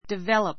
develop 中 A2 divéləp ディ ヴェ ろ プ 動詞 ❶ 発達[発展]させる ; 発達[発展]する The village developed into a large town.